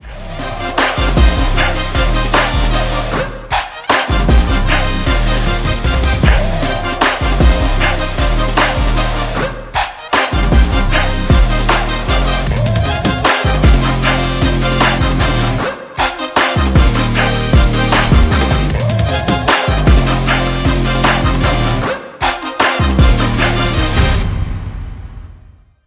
A high-octane, powerful instrumental